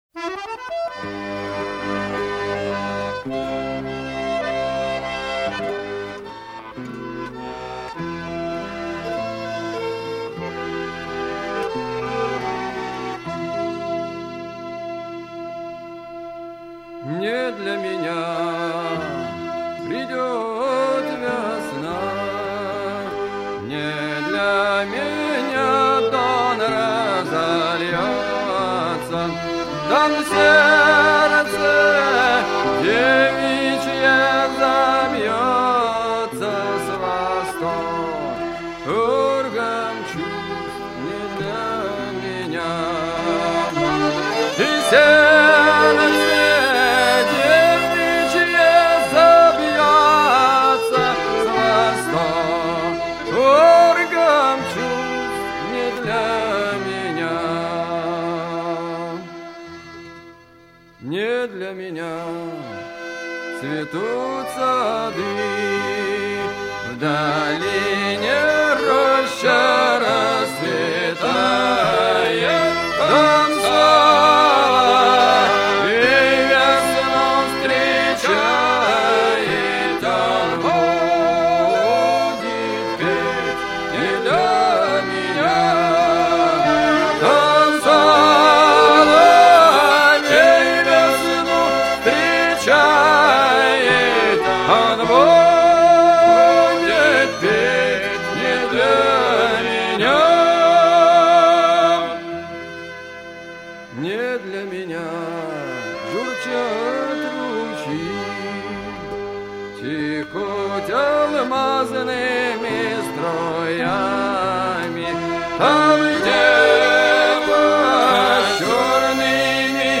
теги: казачий романс
казачий романс, музыка Н.Девитте, стихи А.Молчанова